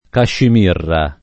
cascimirra [ kaššim & rra ]